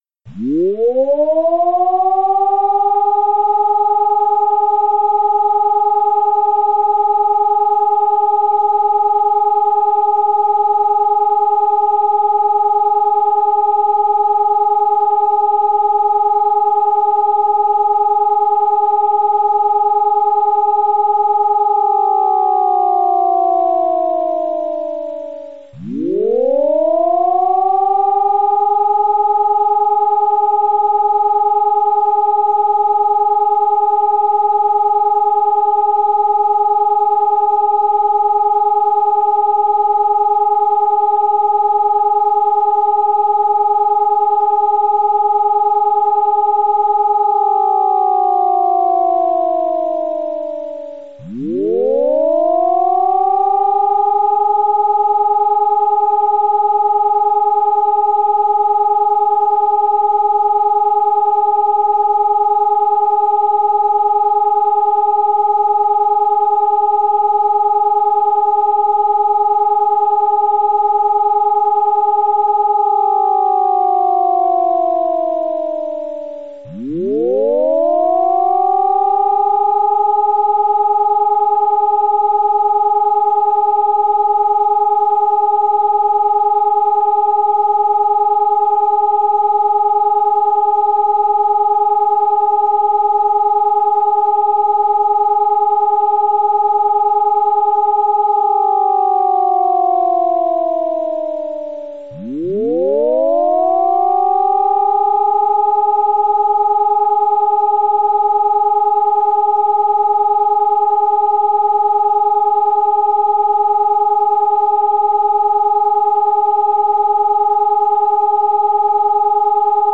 Astăzi, de la ora 10, sirenele din țară vor emite semnalul „Prealarmă aeriană”, care are rolul de prevenire a populației civile asupra apariției pericolului.
„Prealarma aeriană” are rolul de transmitere a semnalelor de avertizare despre probabilitatea producerii unor dezastre sau a unui atac aerian. Sunetul se compune din 3 impulsuri a 32 secunde fiecare cu pauză de 12 secunde între ele.
Cum sună „Prealarma aeriană”
Prealarma-aeriana.mp3